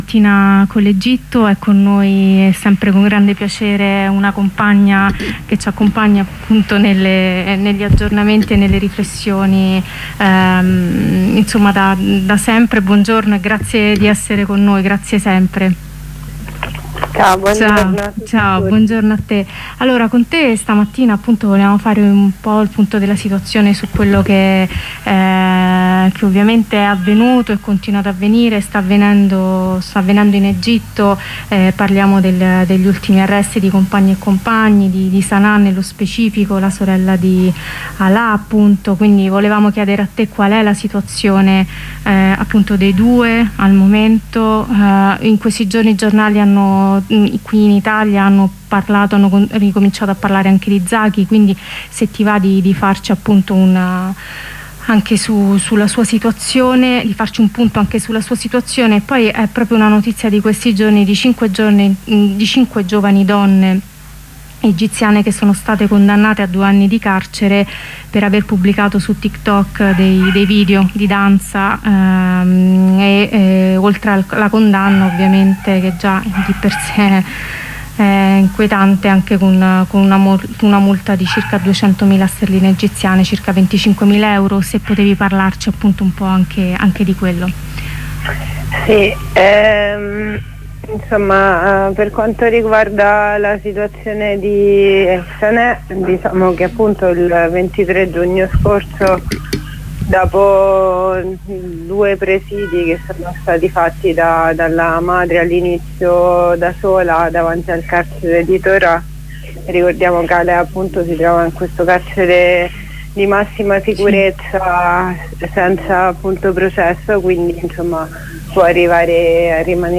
Intervento di presentazione della manifestazione